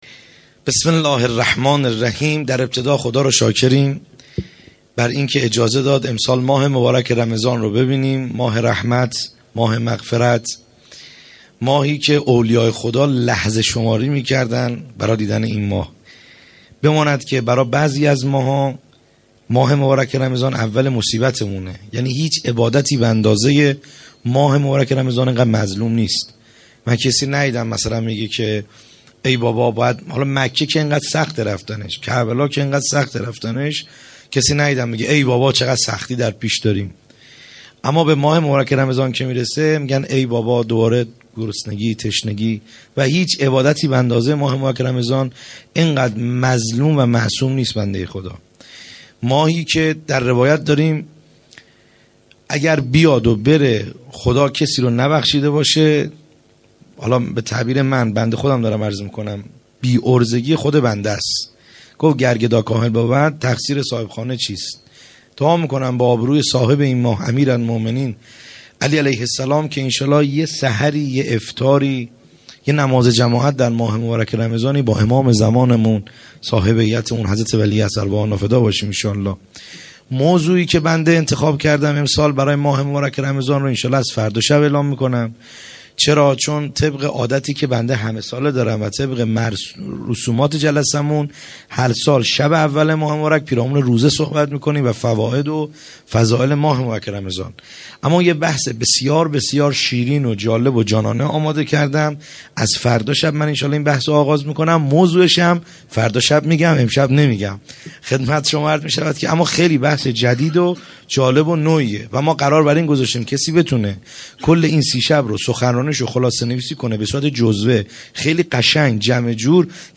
خیمه گاه - بیرق معظم محبین حضرت صاحب الزمان(عج) - سخنرانی | شب دوم